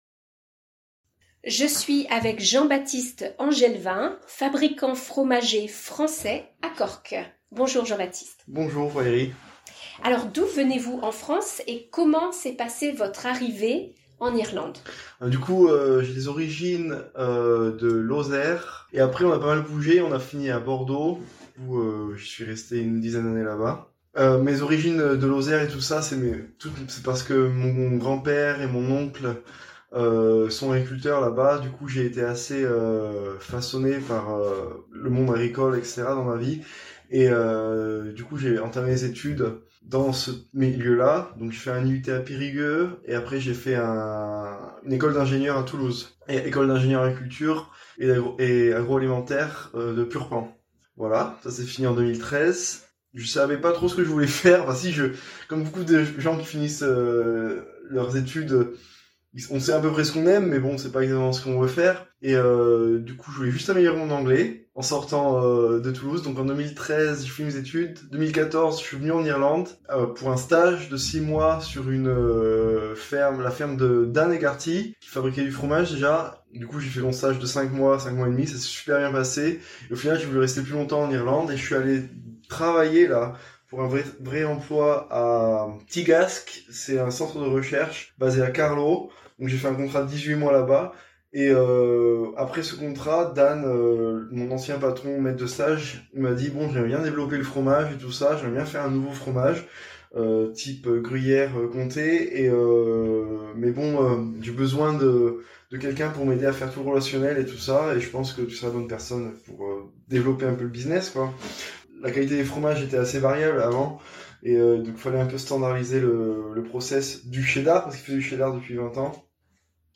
(Interview in French).